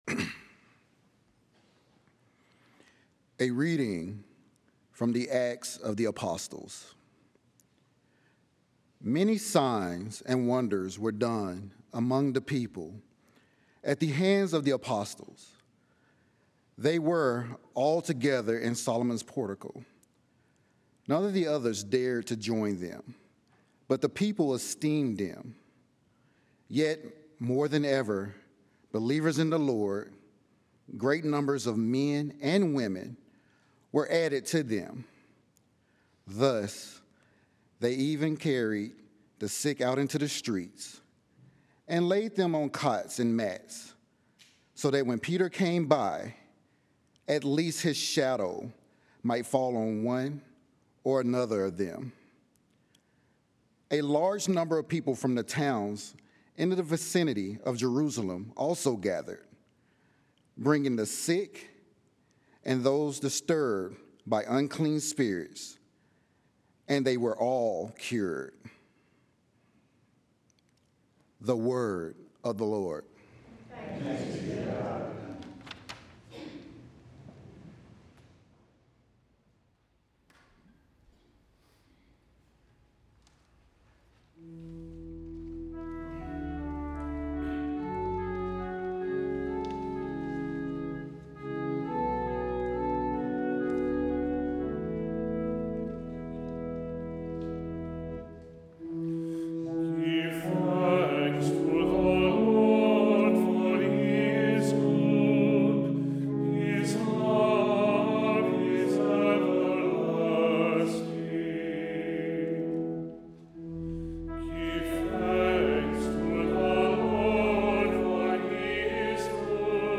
Readings, Homily and Daily Mass
From Our Lady of the Angels Chapel on the EWTN campus in Irondale, Alabama.